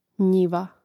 njȉva njiva